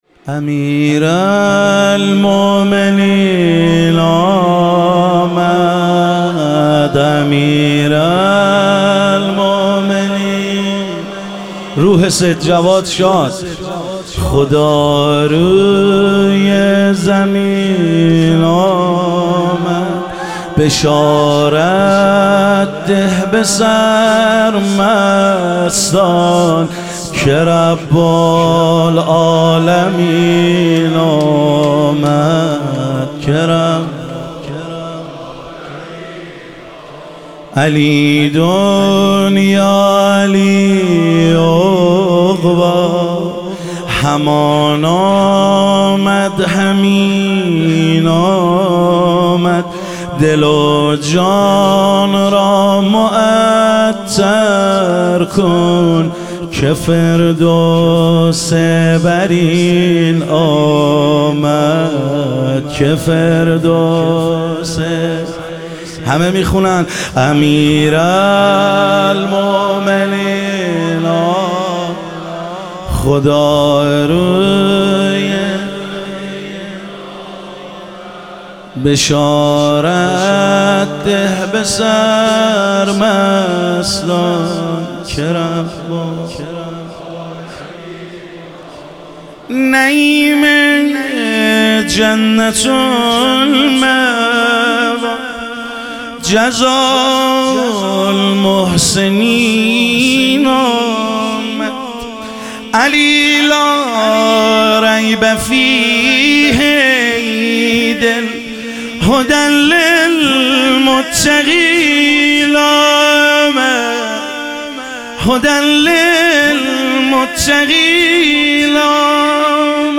ظهور وجود مقدس حضرت امیرالمومنین علیه السلام - مدح و رجز